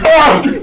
j_pain3.wav